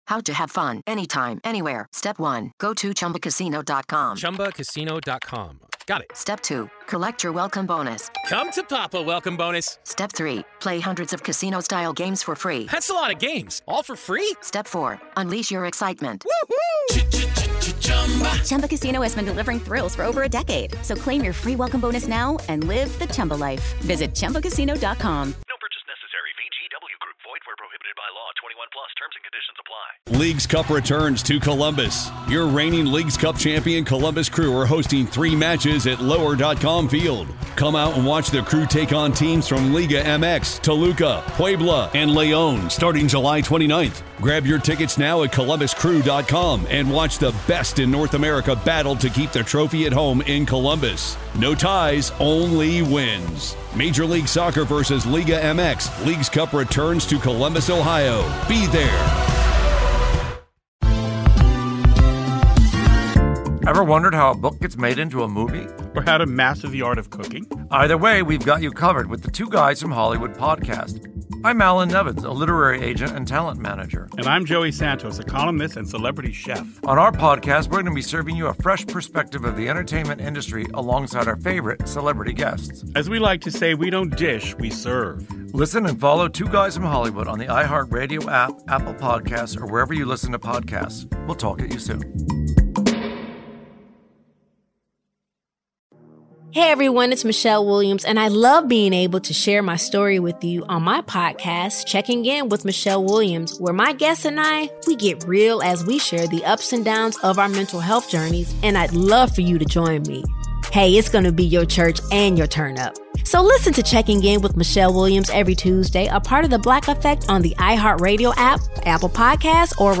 Former MLB catcher JP Arencibia joins the show to talk about the Blue Jays heading into Spring Training, and some catchers he likes heading into the 2020 season.